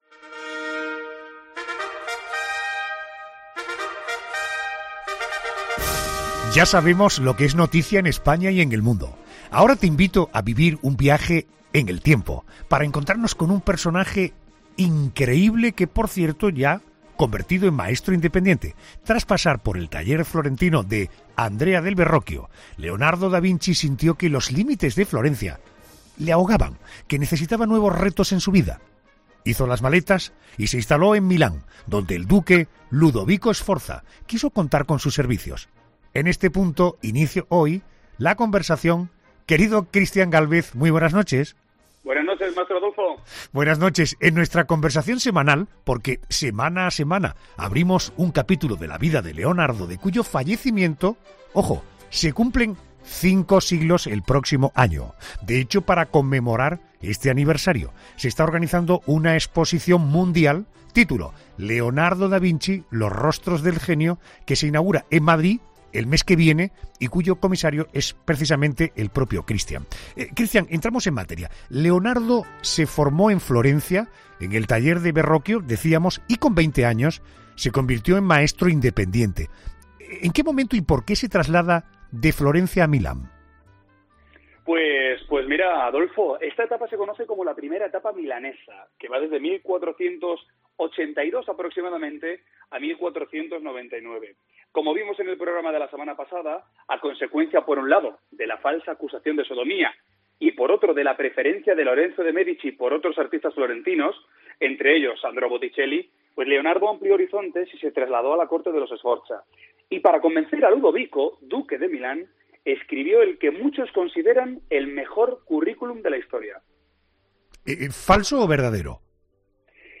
En este punto se inicia hoy la conversación con Christian Gálvez porque semana a semana abrimos un capítulo de la vida de Leonardo, de cuyo fallecimiento se cumplen cinco siglos el próximo año.